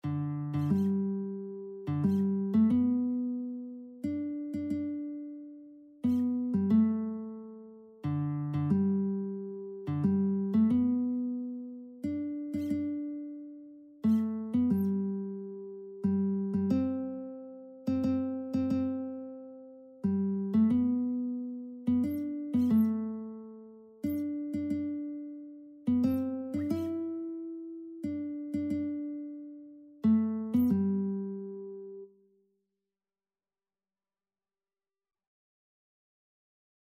Christian Christian Lead Sheets Sheet Music We Have Heard the Joyful Sound
G major (Sounding Pitch) (View more G major Music for Lead Sheets )
ANdante =90
3/4 (View more 3/4 Music)
Classical (View more Classical Lead Sheets Music)